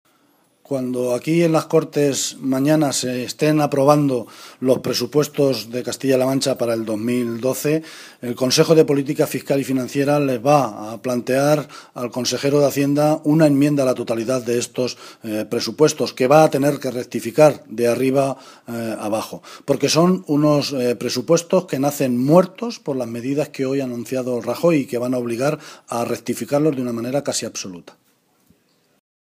José Luis Martínez Guijarro, portavoz del Grupo Parlamentario Socialista
Cortes de audio de la rueda de prensa